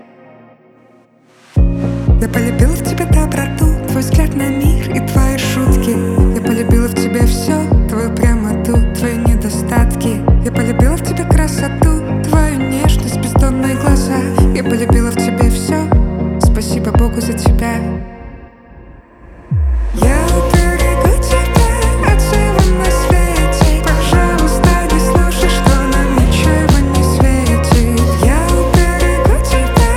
Indie Pop Alternative